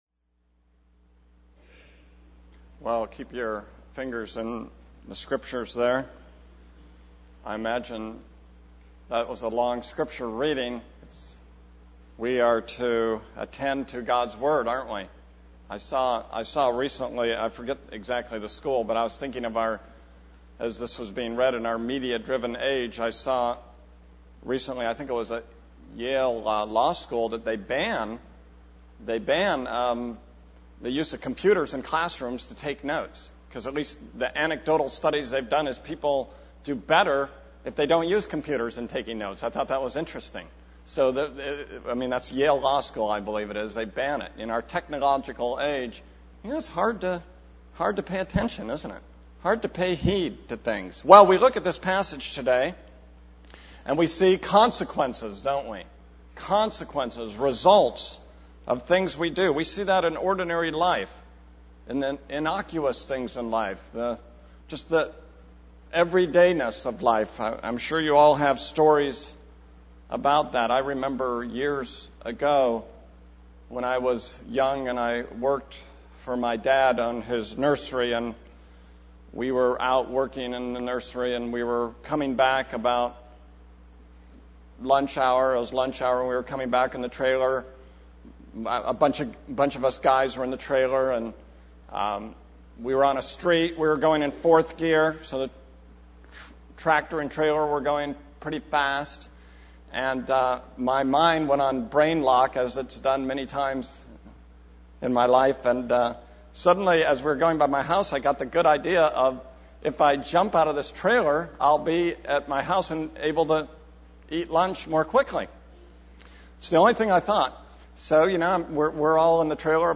This is a sermon on 2 Samuel 13:1-15:12.